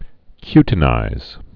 (kytn-īz)